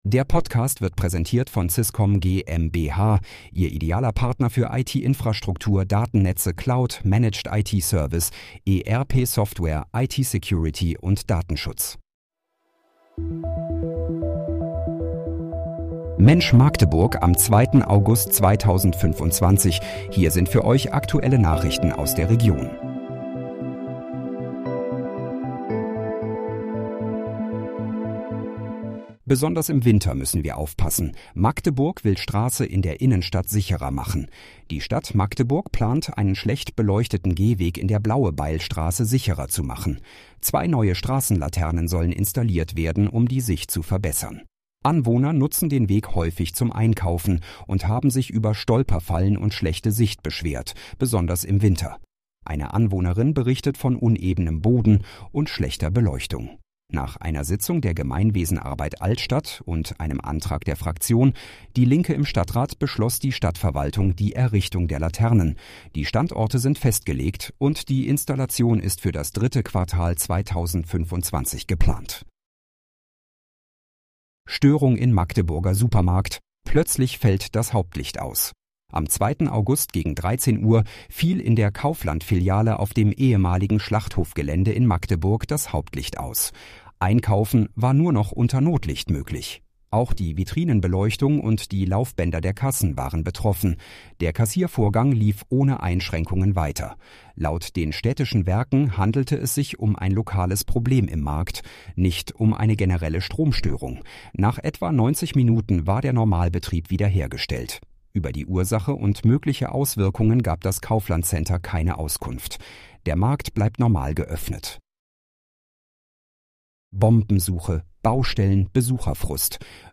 Mensch, Magdeburg: Aktuelle Nachrichten vom 02.08.2025, erstellt mit KI-Unterstützung
Nachrichten